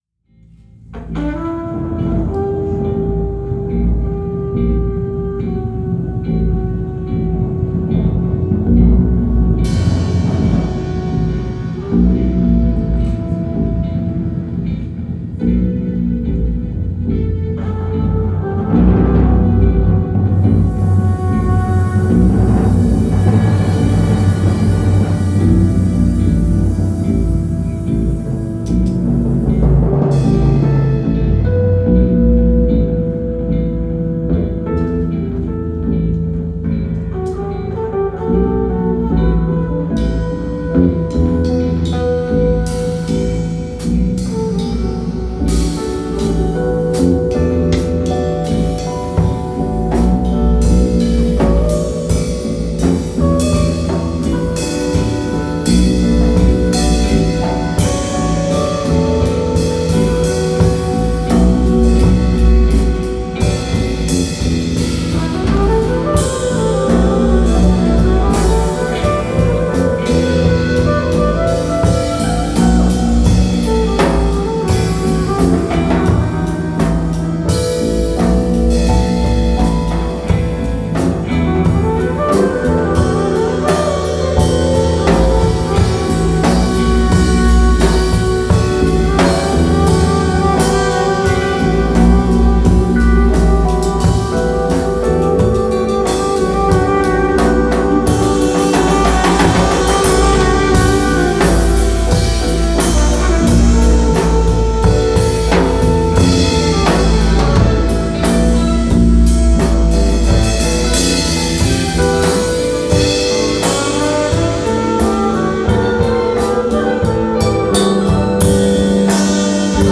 live bei der Jazznacht Soest am 07.02.2026
Keyboard/Synthesizer
Flügelhorn/E-Gitarre
E-Gitarre
Bass/Samples
Schlagzeug